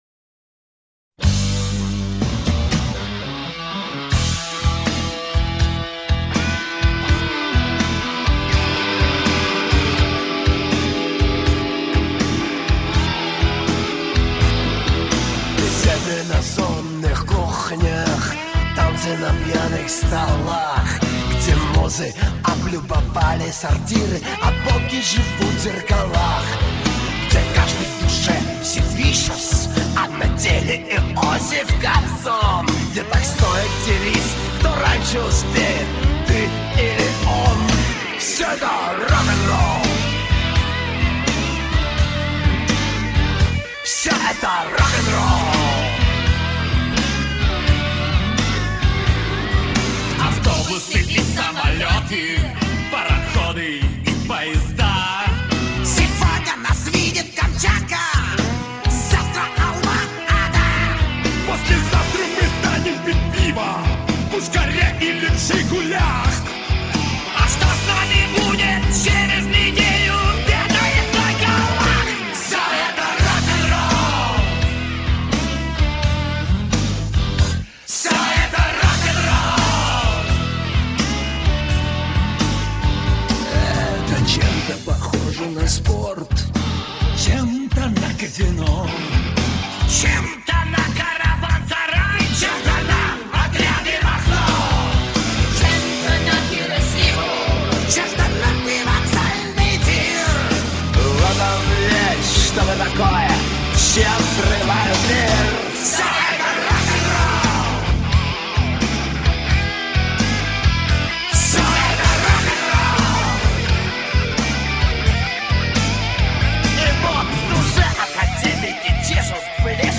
все это рок-н-ролл